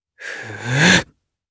Lucias-Vox_Casting2_jp_b.wav